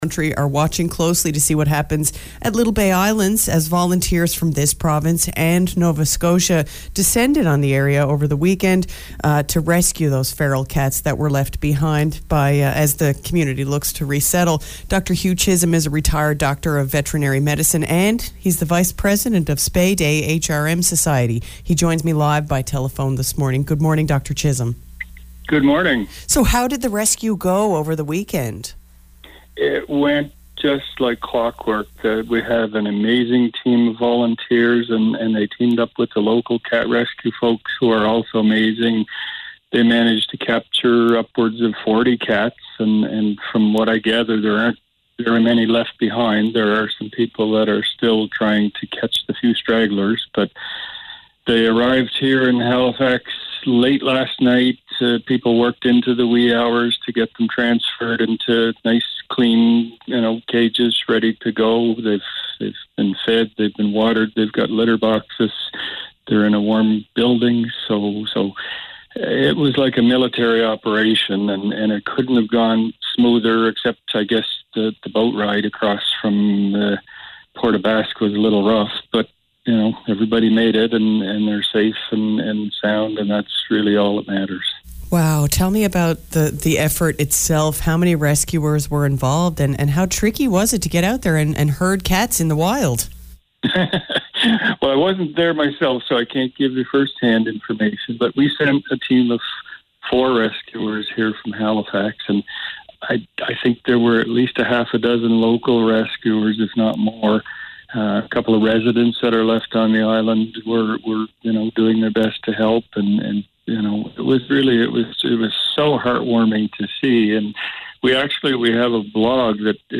Here’s a copy of an interview with VOCM Radio in St. John’s, NL from yesterday:
00bde-vocm-interview.mp3